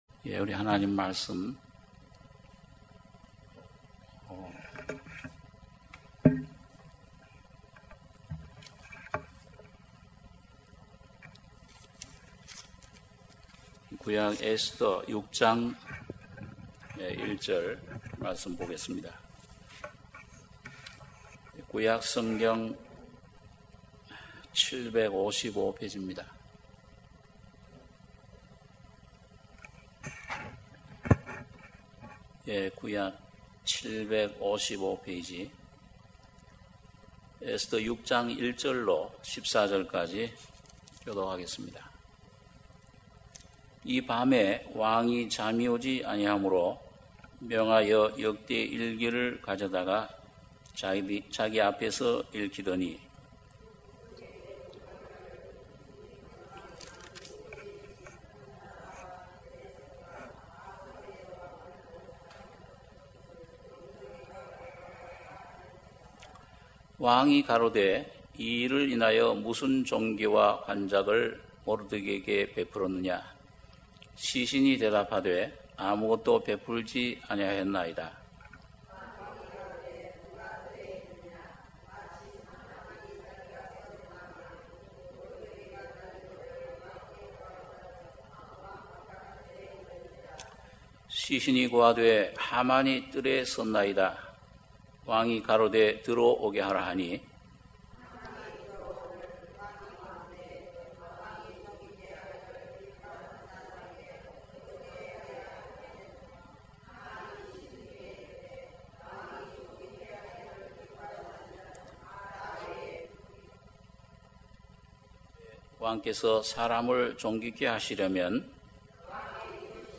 수요예배 - 에스더 6장 1절~ 14절